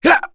Bernheim-Vox_Attack1.wav